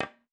Cue Strike Distant.wav